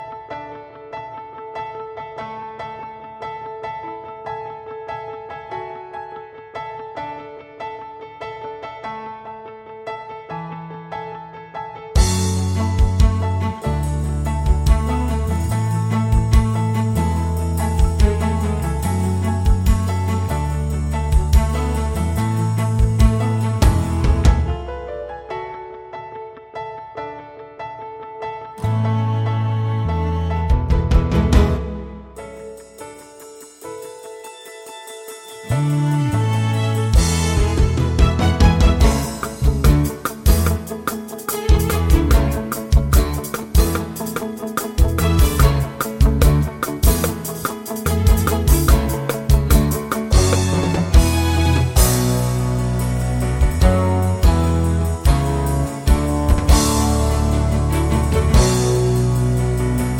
no Backing Vocals Musicals 3:56 Buy £1.50